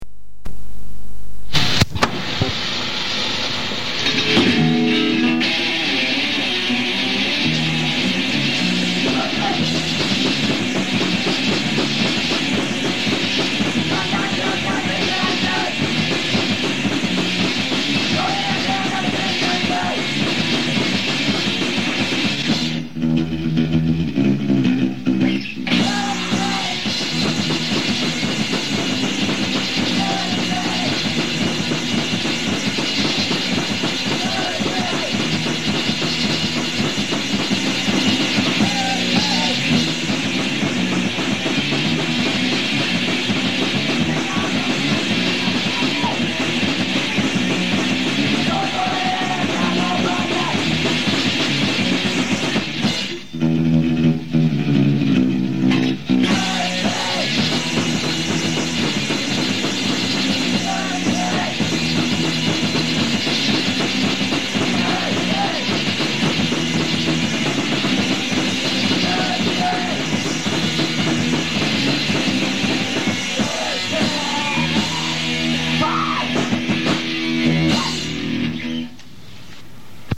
En ganska bra låt med snyggt versriff.